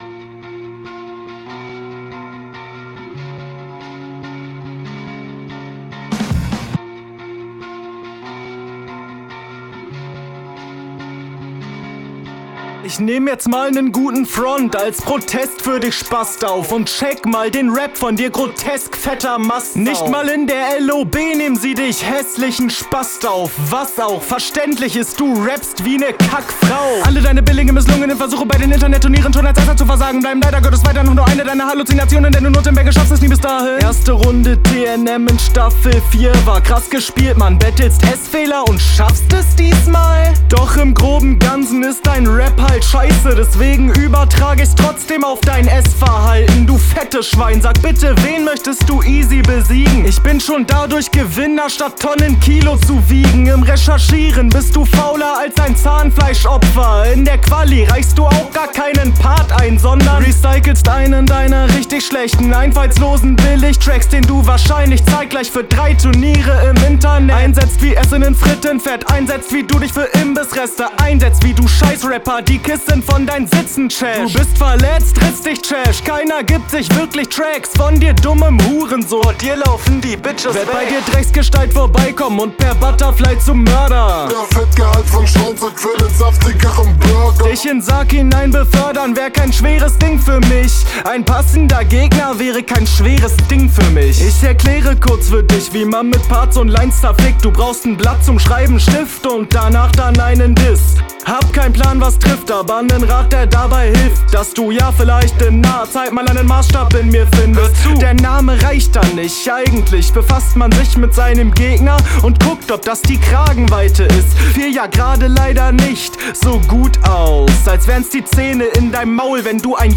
Unfassbar cooler Beat.